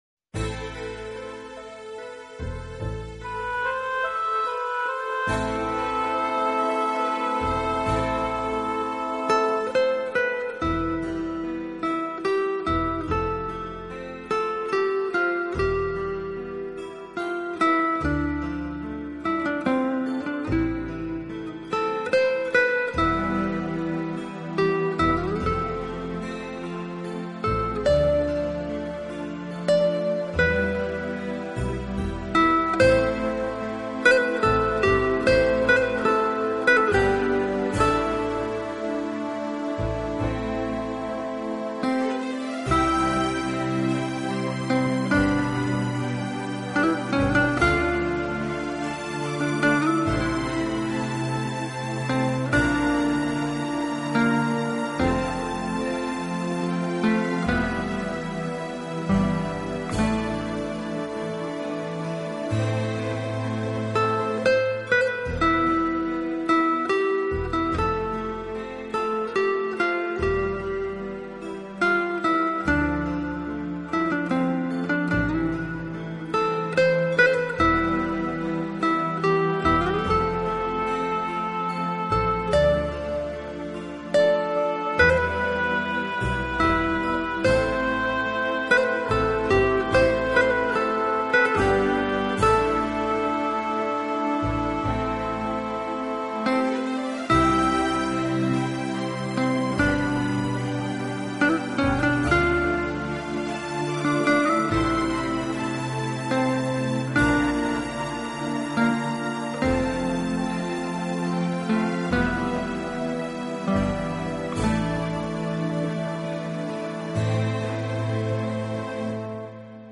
【吉他专辑】
醇和，柔美华丽的音色，颗粒饱满，光辉璀璨的音质，清晰的层次
丰富的和弦，一切你能想到的和希望的精华都在本辑中展露无遗。